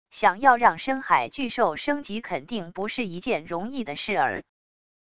想要让深海巨兽升级肯定不是一件容易的事儿wav音频生成系统WAV Audio Player